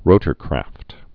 (rōtər-krăft)